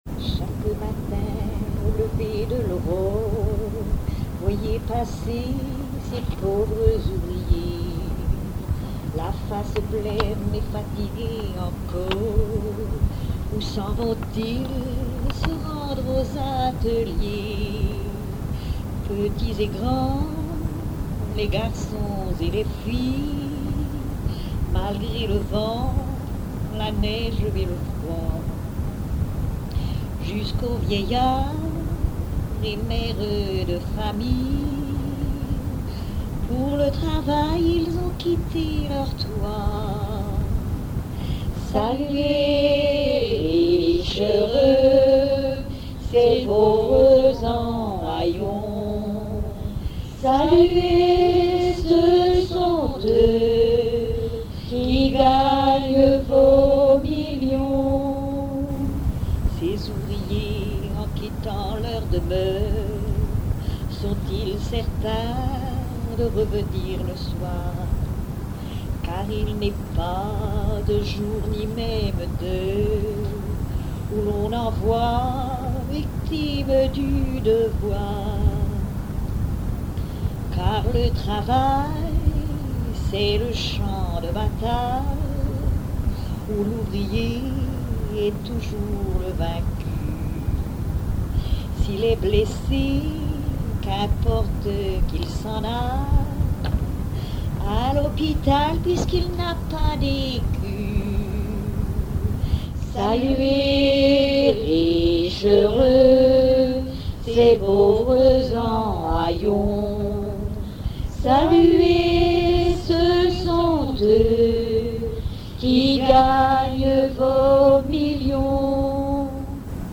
Genre strophique
Enquête Douarnenez en chansons
Catégorie Pièce musicale inédite